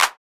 BTTRF - CLAP.wav